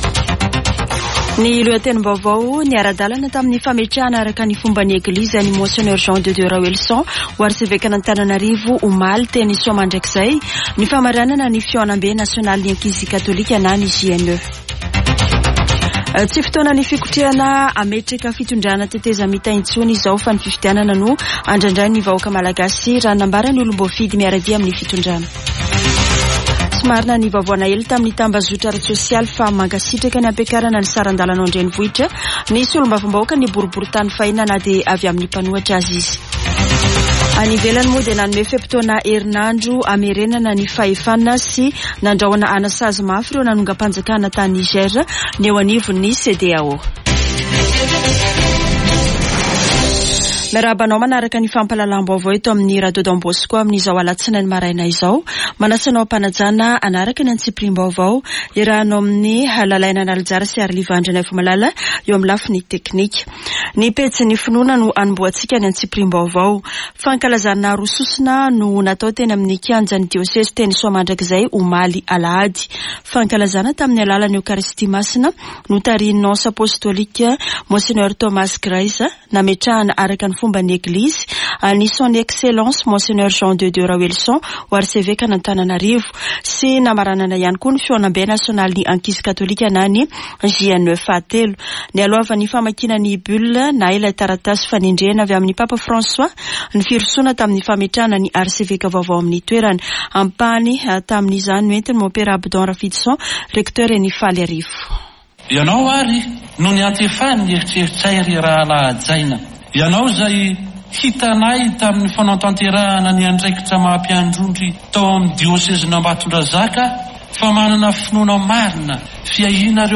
[Vaova maraina] Alatsinainy 31 jolay 2023